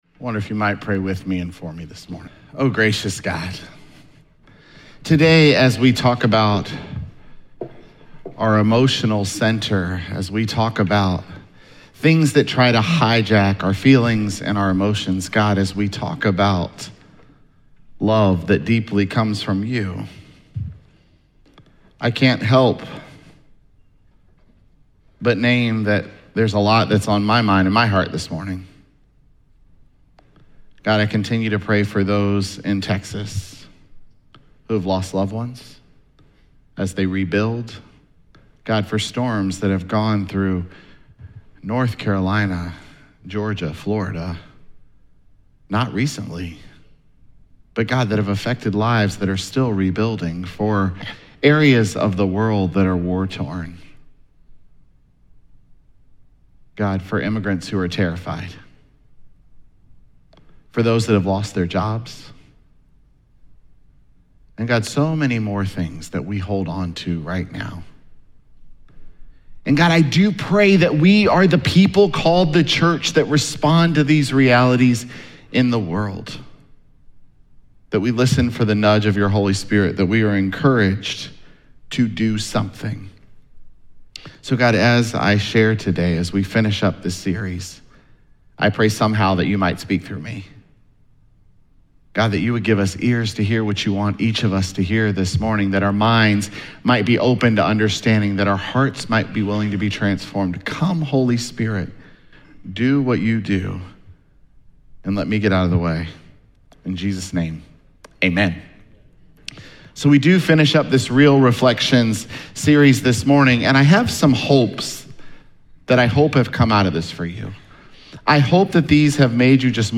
Sermons
Jul13SermonPodcast.mp3